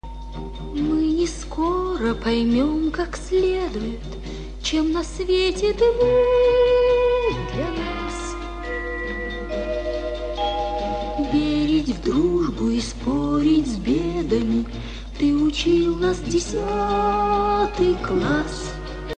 Еще раз отдельно выкладываю - ну Толкуновские же нотки.